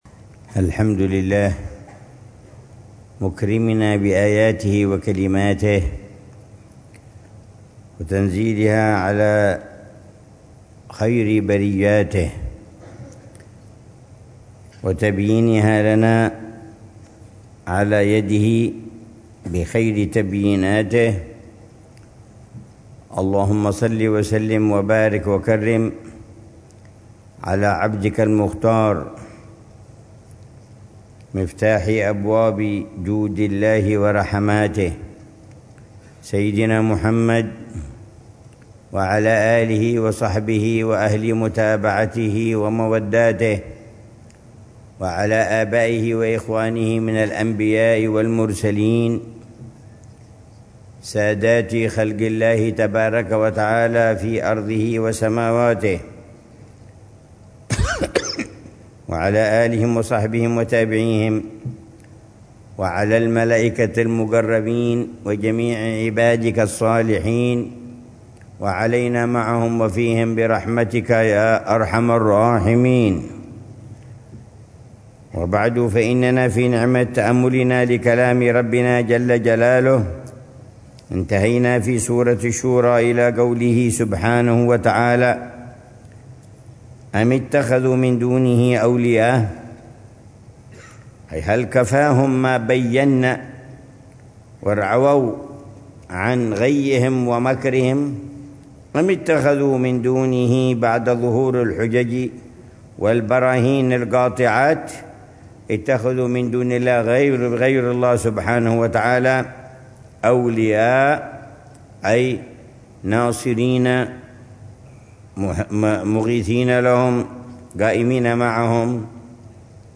الدرس الثالث من تفسير العلامة عمر بن محمد بن حفيظ للآيات الكريمة من سورة الشورى، ضمن الدروس الصباحية لشهر رمضان المبارك من عام 1446هـ